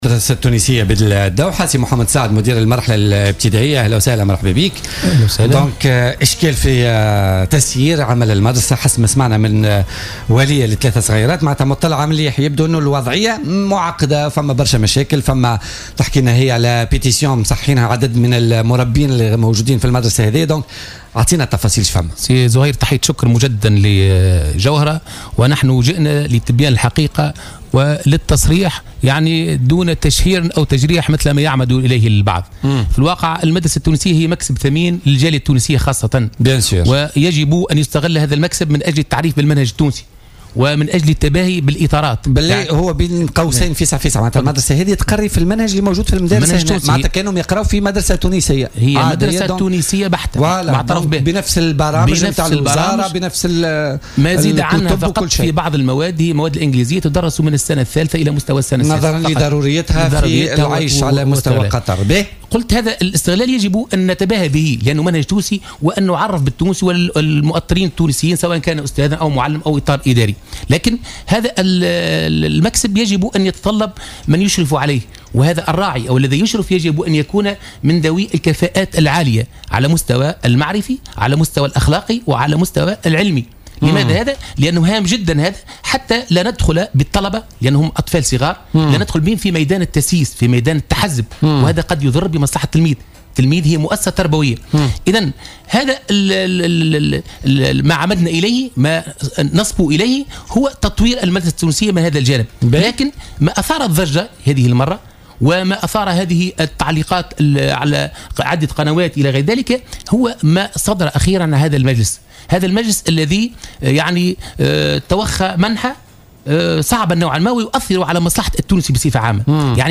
ودعا الضيفان وزارة التربية للتدخل لتجميد صلاحيات هذا المجلس الذي تم انتخابه بعد الثورة التونسية.